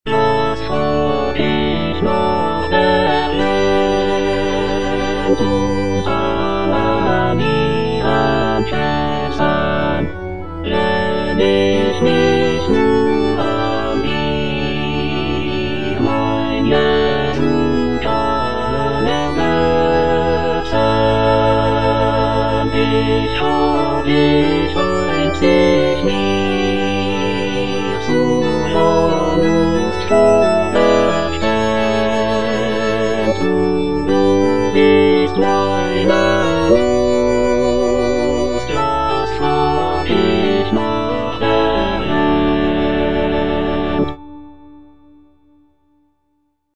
Choralplayer playing Cantata
J.S. BACH - CANTATA "SEHET, WELCH EINE LIEBE" BWV64 Was frag' ich nach der Welt (All voices) Ads stop: auto-stop